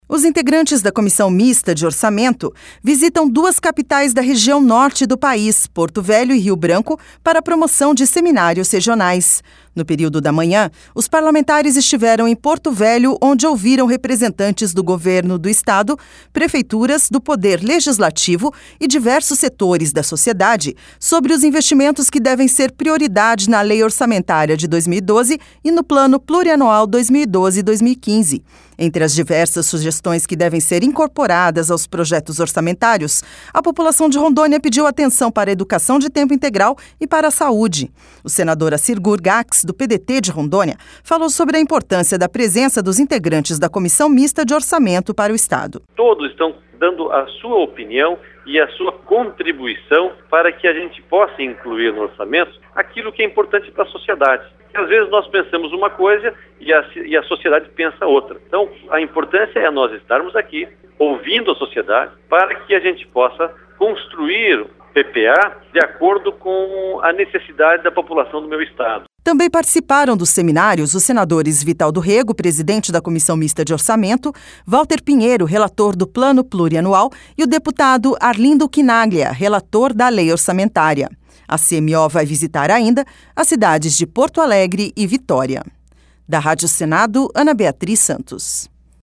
Entre as diversas sugestões que devem ser incorporadas aos projetos orçamentários, a população de Rondônia pediu atenção para a educação de tempo integral e para a saúde. O senador Acir Gurgacz, do PDT de Rondônia, falou sobre a importância da presença dos integrantes da comissão mista de orçamento para o estado.